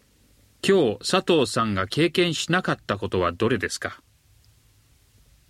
Conversation 02